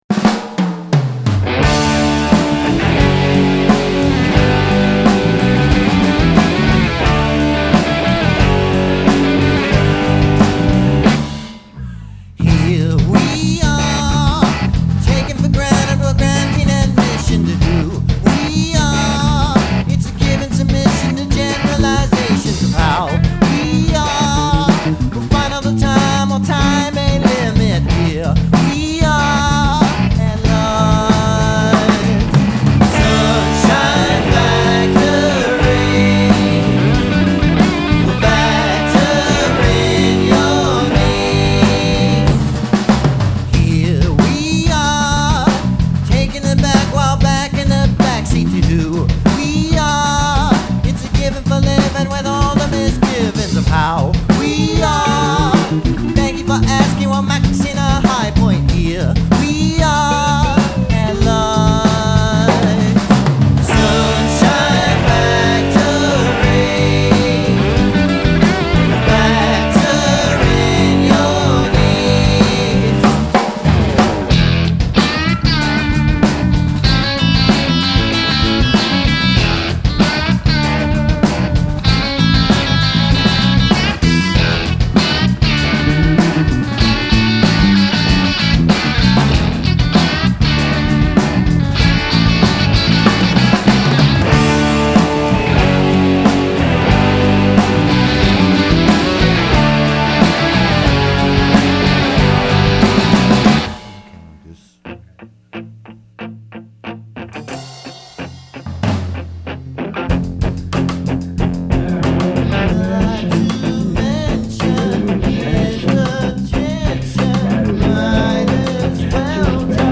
• Power Pop
• Full complement micing with close and room mics